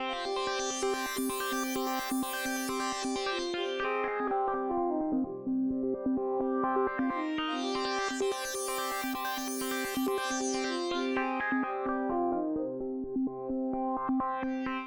01 seq pad B2.wav